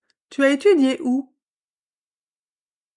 #7. Listen to this. What is she saying?